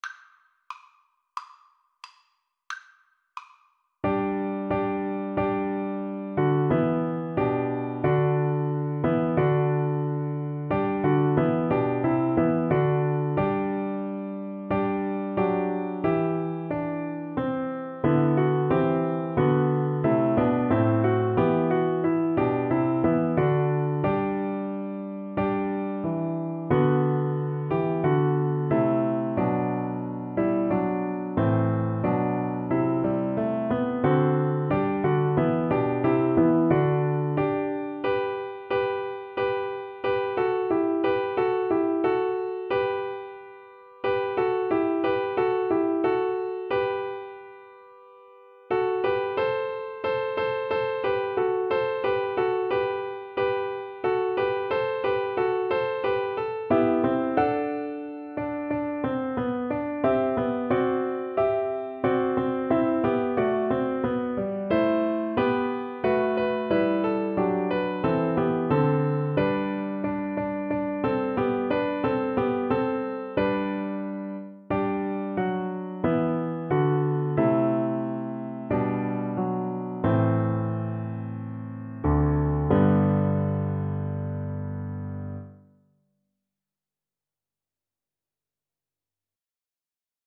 Play (or use space bar on your keyboard) Pause Music Playalong - Piano Accompaniment Playalong Band Accompaniment not yet available reset tempo print settings full screen
G major (Sounding Pitch) (View more G major Music for Flute )
Classical (View more Classical Flute Music)